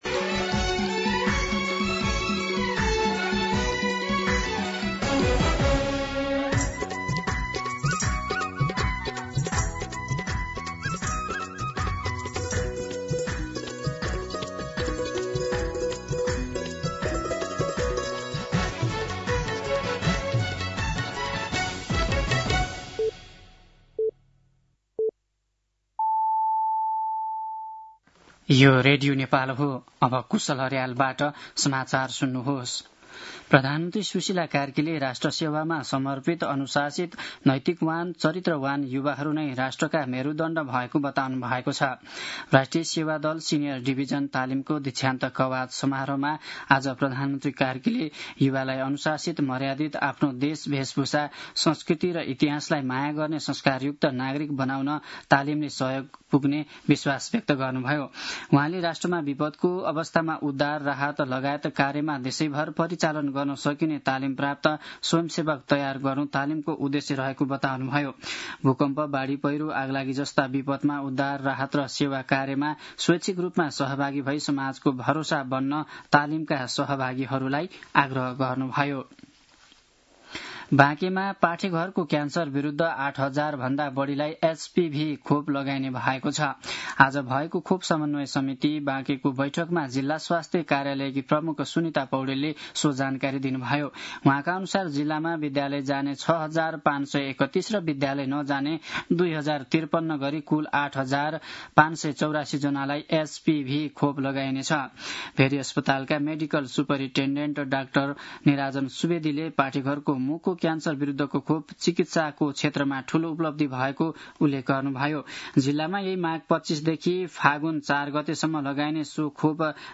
दिउँसो ४ बजेको नेपाली समाचार : २० माघ , २०८२
4pm-News-10-20.mp3